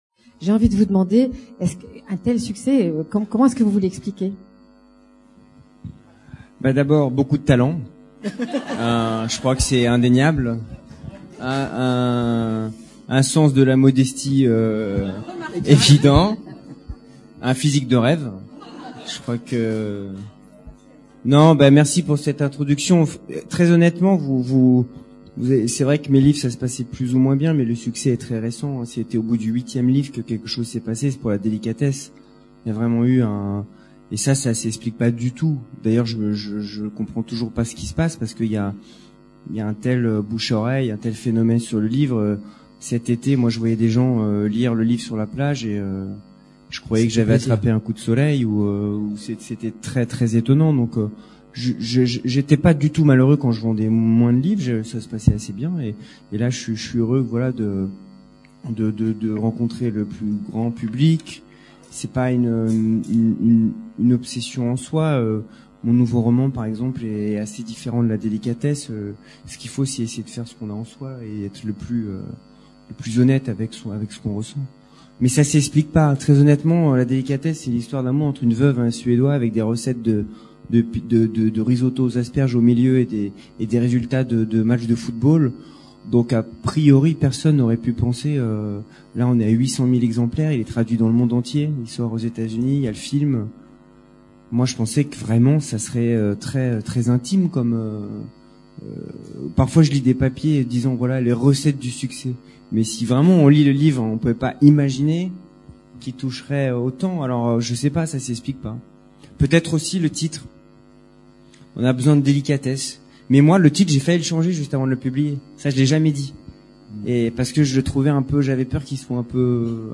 Foenkinos, David. Personne interviewée
Rencontre littéraire